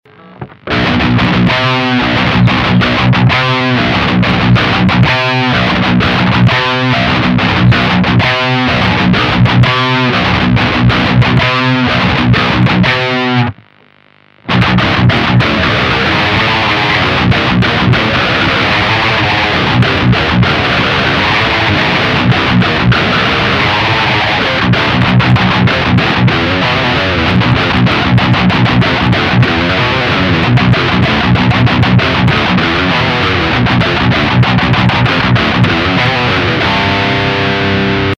13:56:21 » Звук действительно фигня - тупой и глухой.